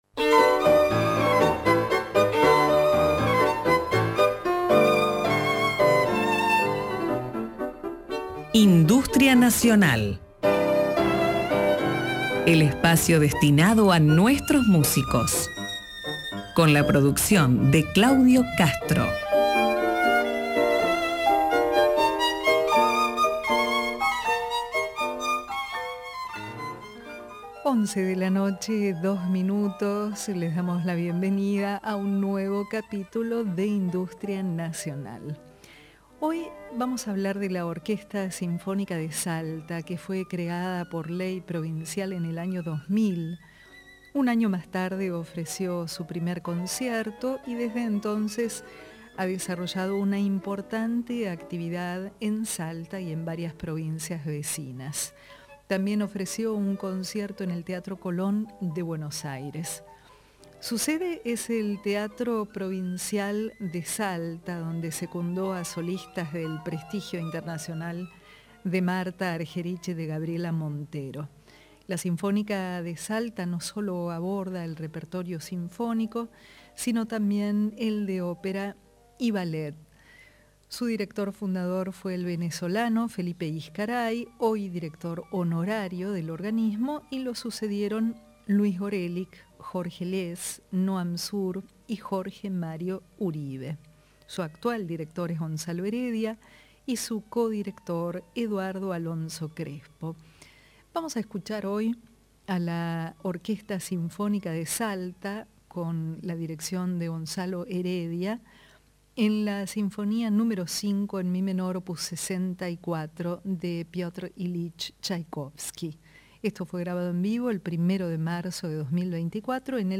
Los dejamos entonces en sus manos para escuchar esta soberbia versión en vivo de la Sinfonía N°5 en mi menor, op.64 de Chaikovsky.